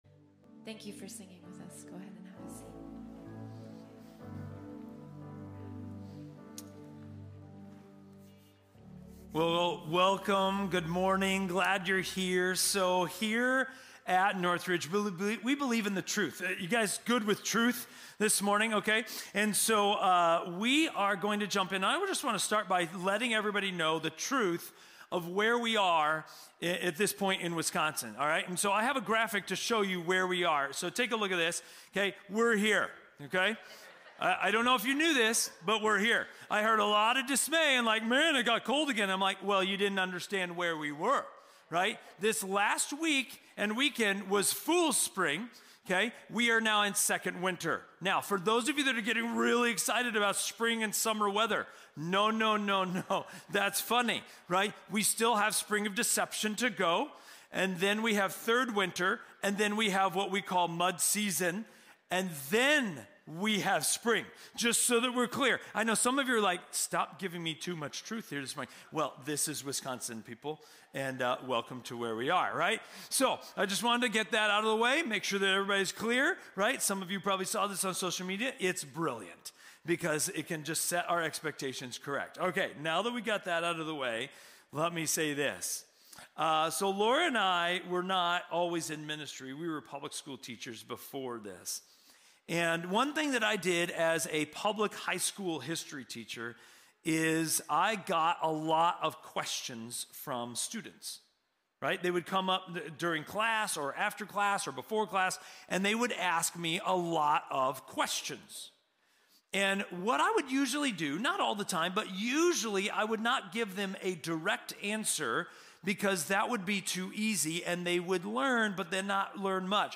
These are the weekly messages from North Ridge Church in Waunakee, Wisconsin.